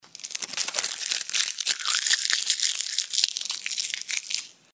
Звуки скотча
2. Удаление избыточного скотча